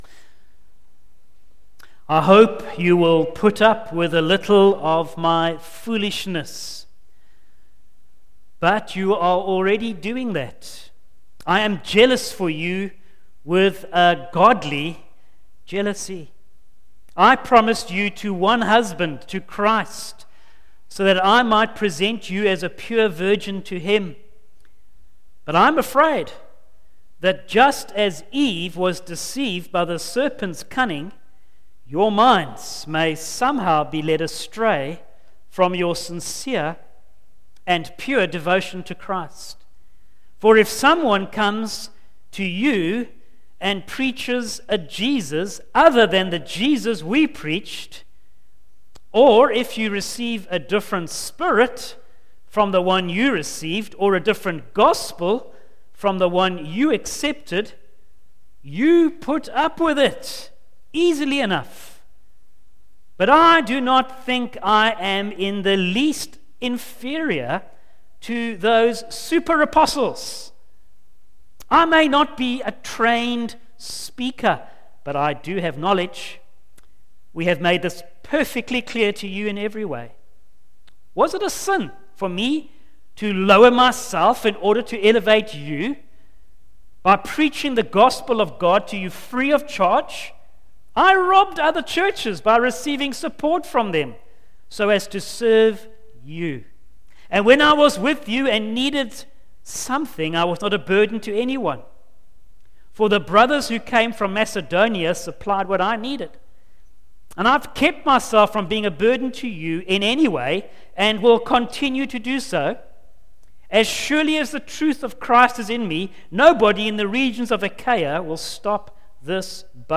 Facebook Twitter email Posted in Evening Service